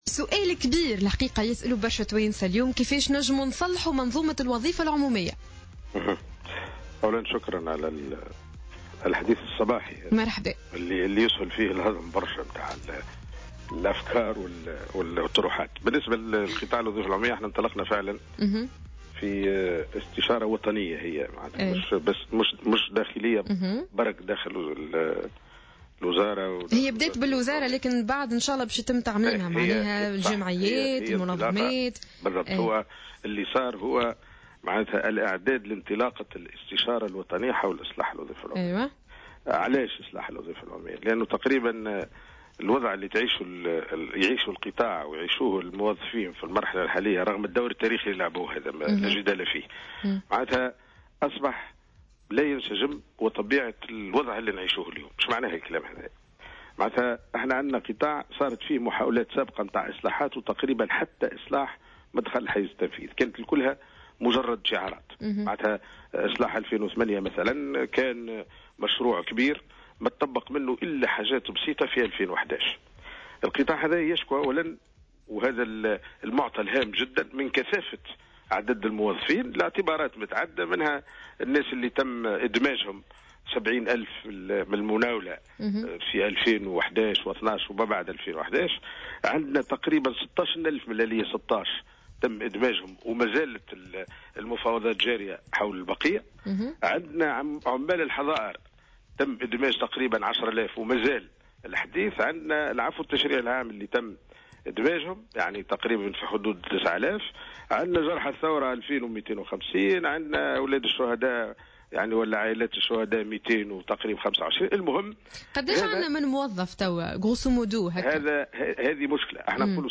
وأكد الوزير في تصريح اليوم ل"الجوهرة أف أم" عدم وجود توازن داخل الإدارات من حيث عدد الموظفين، مشيرا إلى أن عديد القطاعات تشكو من كثافة في عدد العمال، خاصة بعد الانتدابات التي شهدتها المؤسسات العمومية ( 70 ألف من عمّال المناولة و16 ألف انتداب من الآلية 16 و10 آلاف من عمال الحضائر و حوالي 9 آلاف من المنتفعين بالعفو التشريعي، إضافة إلى جرحى الثورة وعائلات الشهداء).